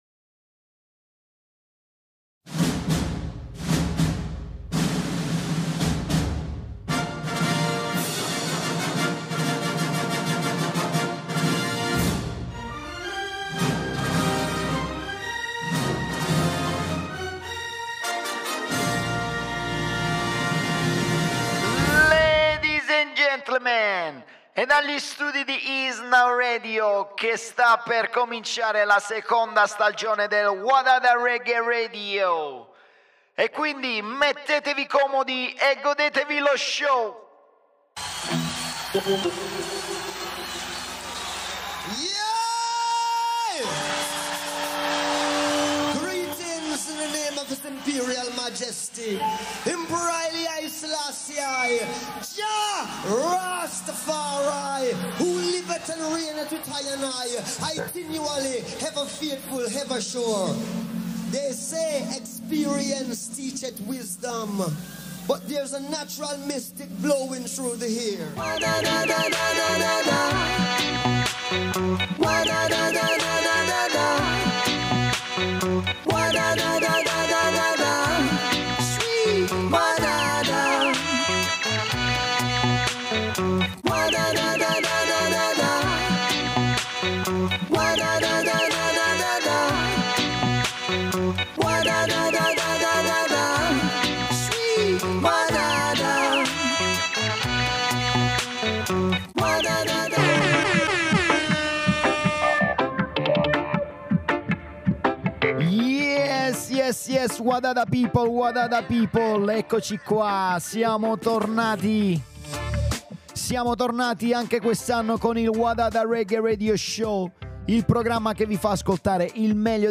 Roots Reggae Dub Music